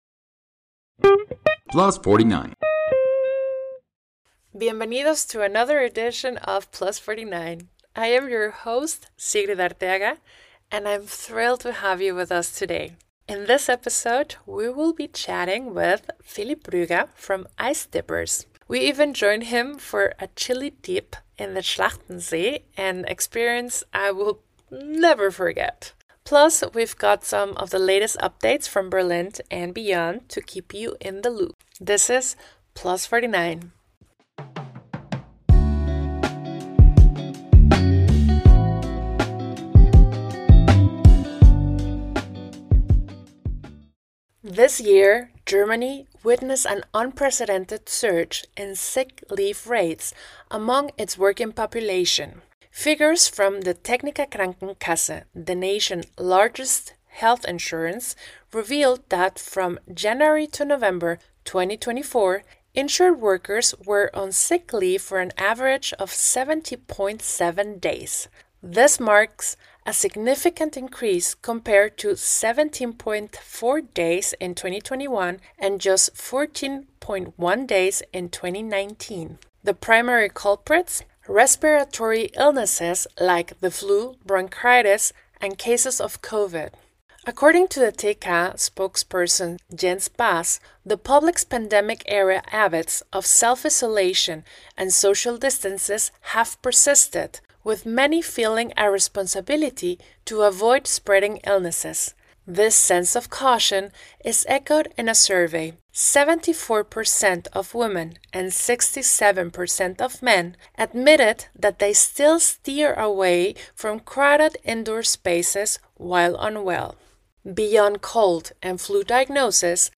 Ever had the urge to jump into ice-cold water? If not, today’s interview might just inspire you to take the plunge!